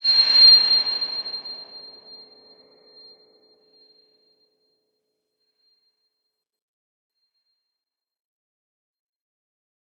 X_BasicBells-C6-ff.wav